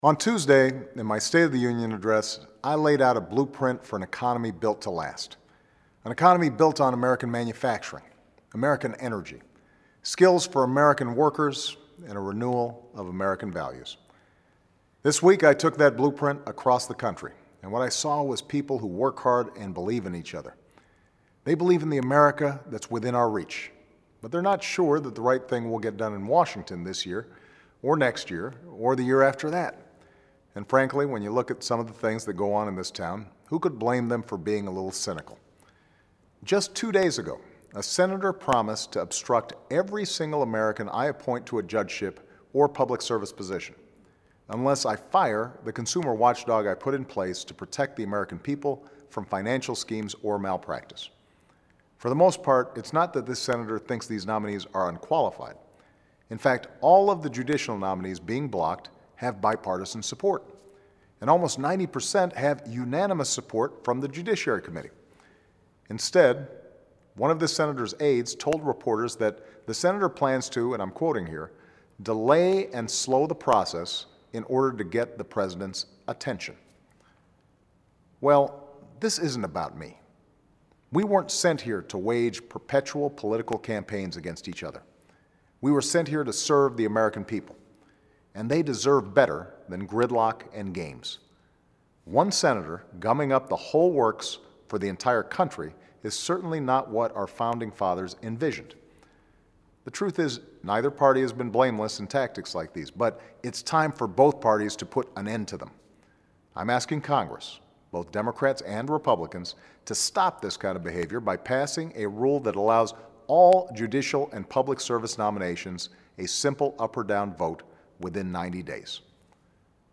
Remarks of President Barack Obama